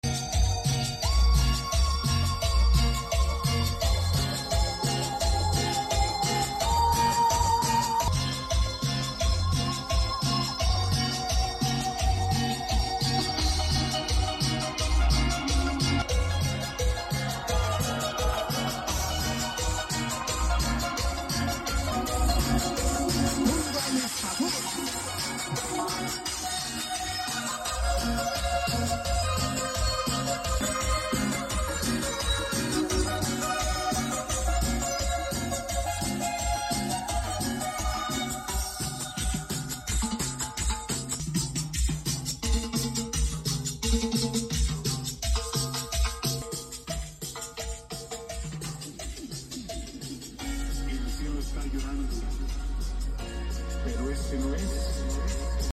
Preparación con subwoofers DB SOUND y amplificadores Taramps